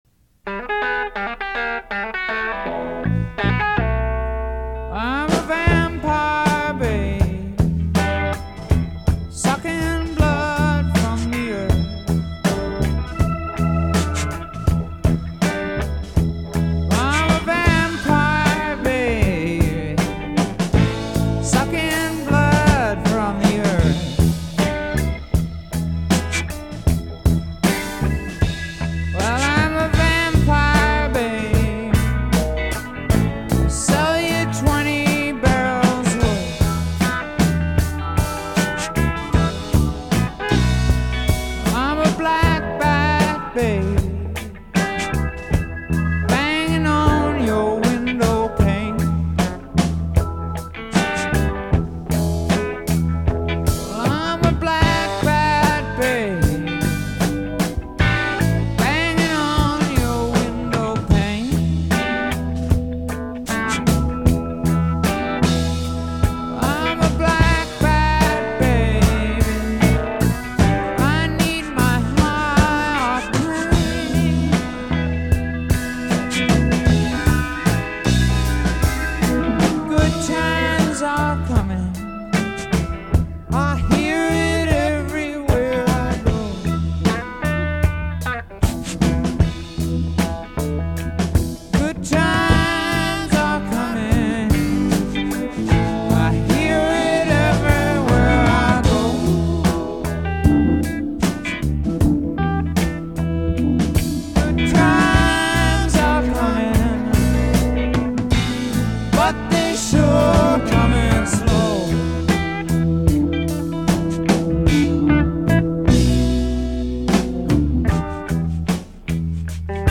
Downbeat, downplayed and downtrodden